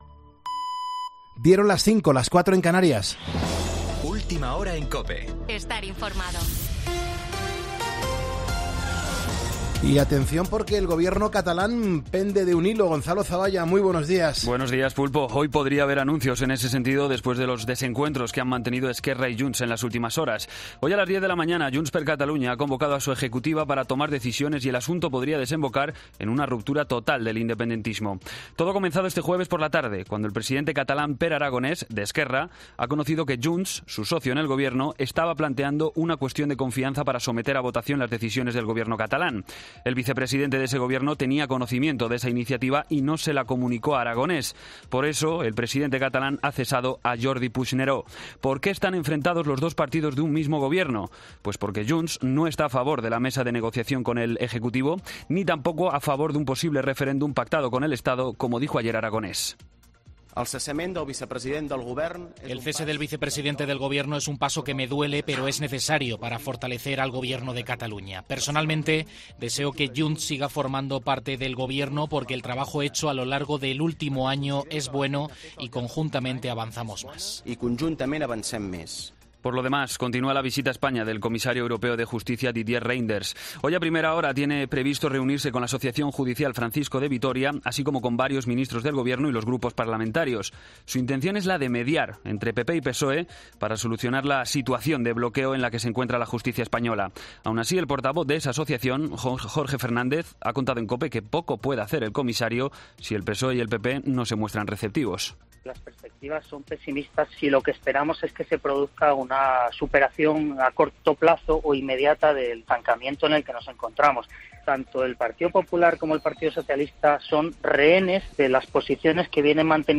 Boletín de noticias COPE del 29 de septiembre a las 05:00 hora
AUDIO: Actualización de noticias Herrera en COPE